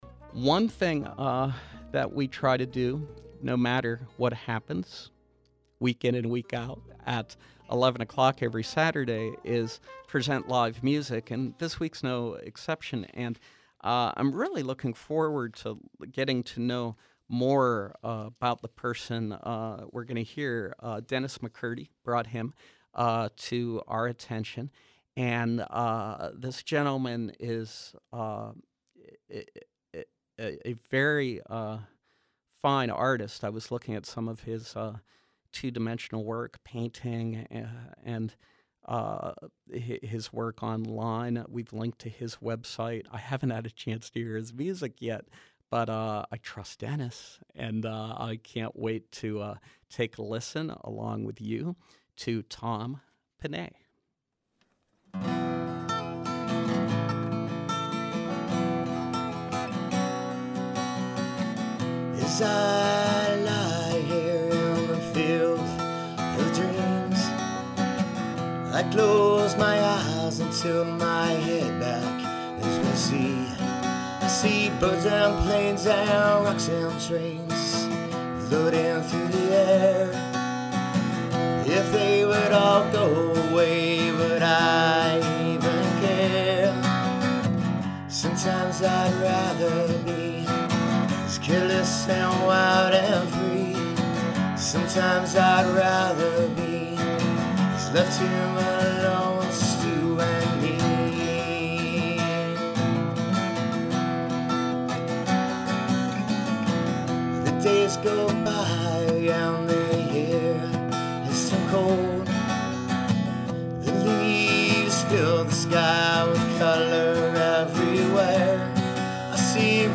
performs live in our studios